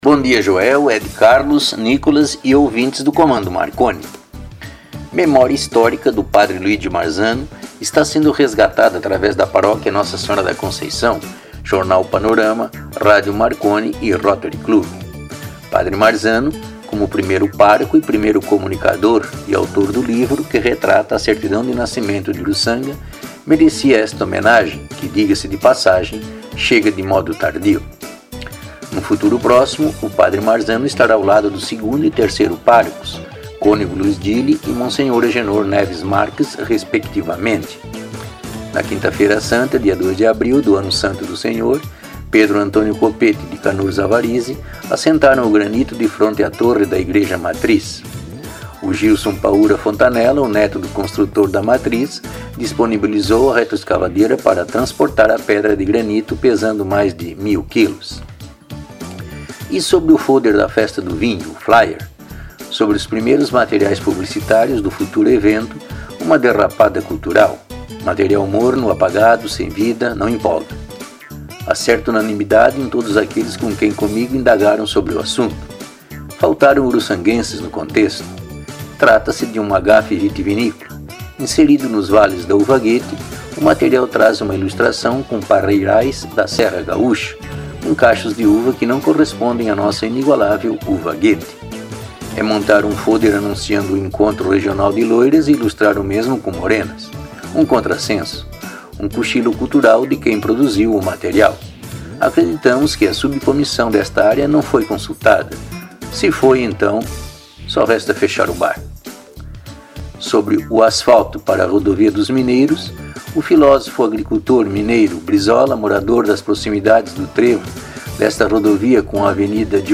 A atração é apresentada de modo espirituoso e com certas doses de humorismo e irreverência, além de leves pitadas de ironia quando necessárias.
As crônicas são veiculadas pela Rádio Marconi 99.9 FM nas segundas, quartas e sextas-feiras durante os programas Comando Marconi e Giro Final.